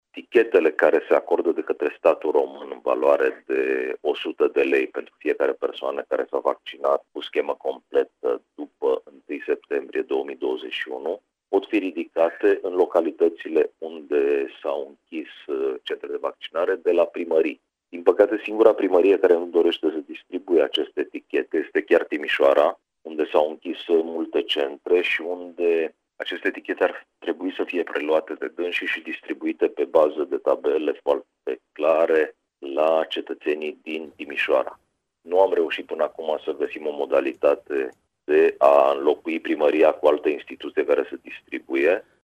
Subprefectul a precizat, la Radio Timișoara, că toate celălalte administrații le-au preluat pentru a le da beneficiarilor.